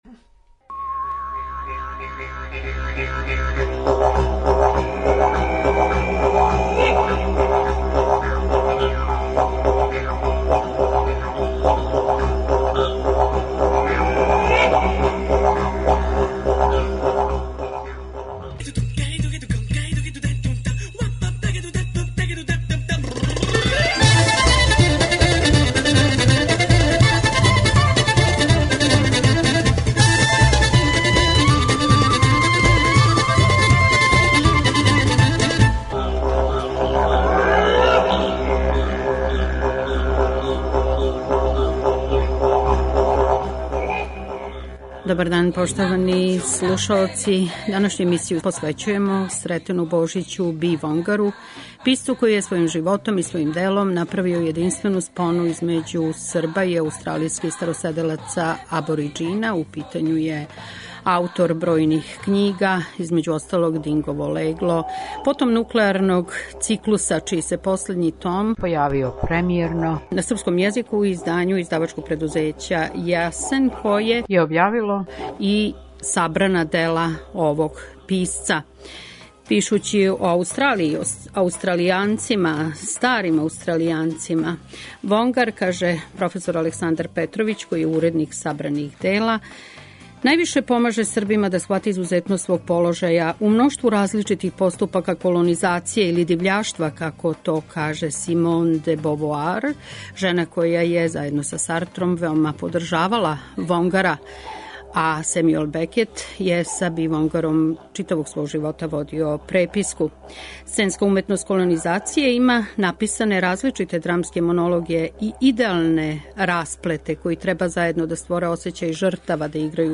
У прилици сте да чујете разговор са Б. Вонгаром, савременим српским писцем који је светску славу стекао пишући у Аустралији о животу и страдању староседелаца аустралијског континента.